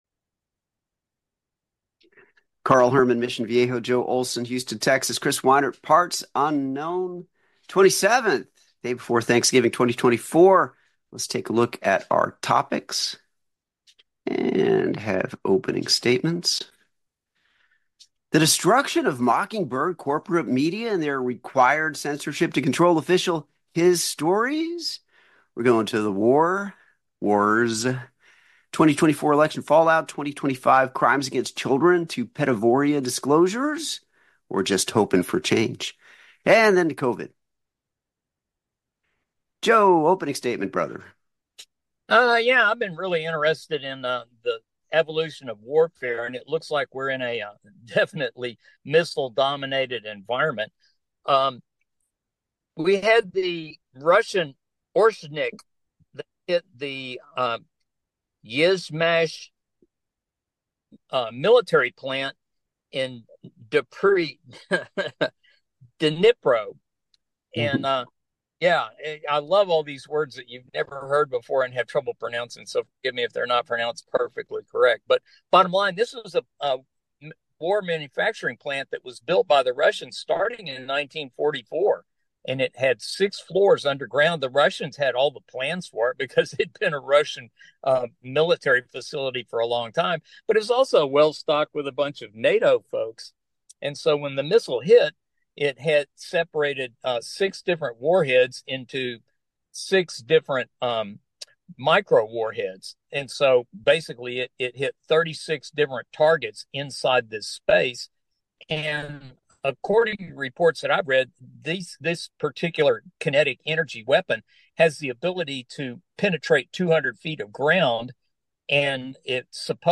SPREAD THE WORD MP3 Audio Summary ➡ The discussion revolves around various topics including the evolution of warfare, the impact of missiles, the distrust in corporate media, and the political landscape. The conversation highlights a missile attack on a Russian military plant, the role of media in shaping public opinion, and the perceived lack of accountability in politics.